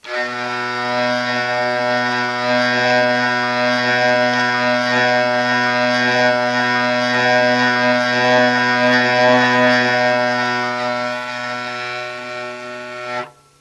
描述：第一个bordone在一个hurdygurdy上玩
Tag: ghironda 六弦琴-A -roue 手摇风琴